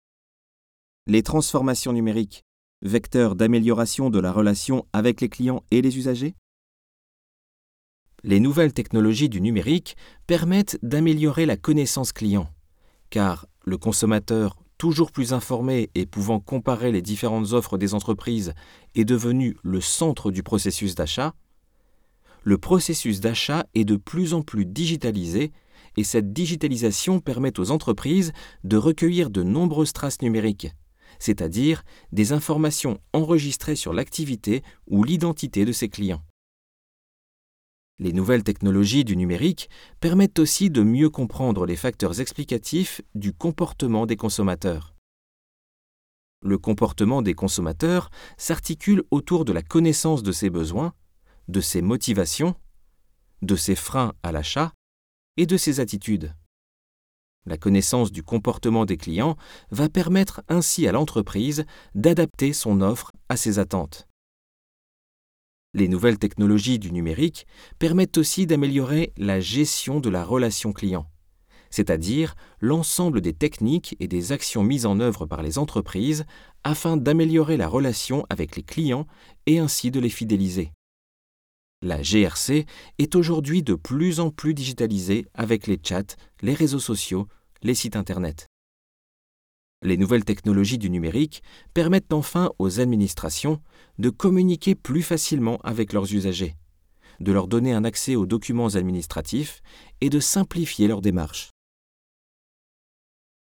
Synthèse audio du chapitre